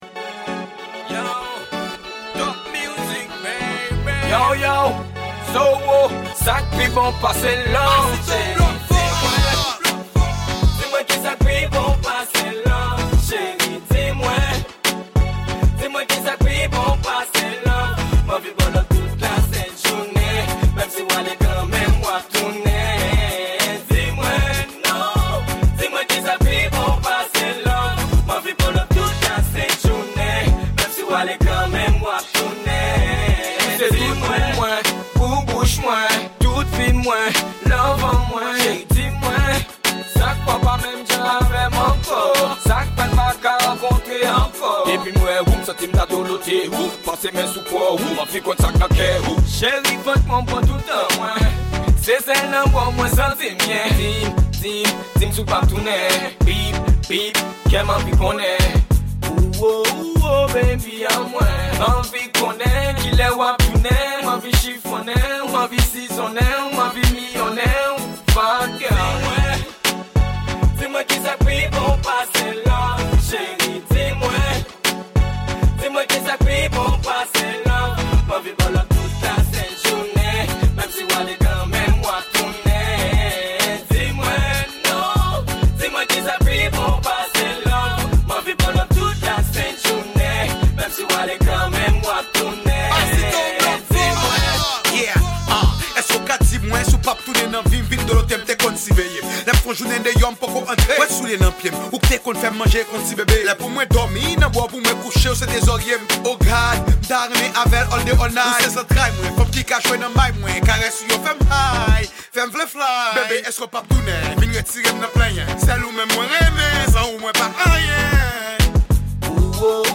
Genre: Dancehal.